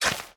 Minecraft Version Minecraft Version snapshot Latest Release | Latest Snapshot snapshot / assets / minecraft / sounds / item / shovel / flatten4.ogg Compare With Compare With Latest Release | Latest Snapshot
flatten4.ogg